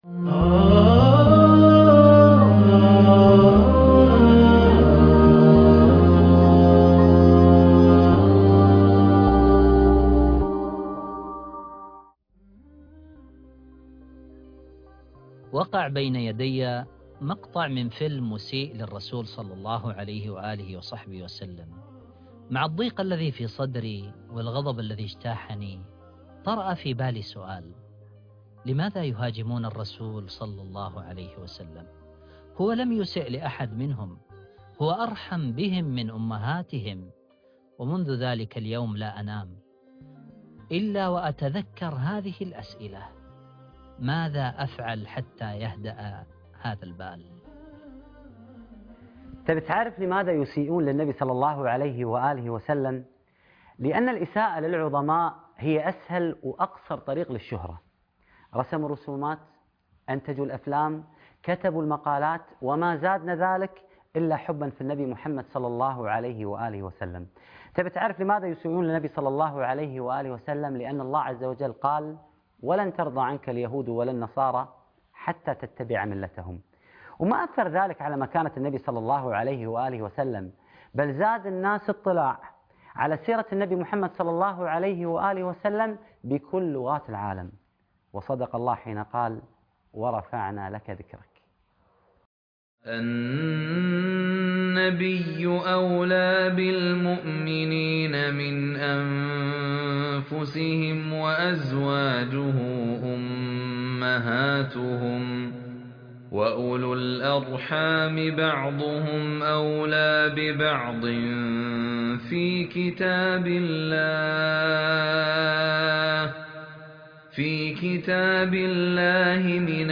علمني القرآن الحلقة الثالثة و العشرون _ الإساءة للنبي - القاريء فهد الكندري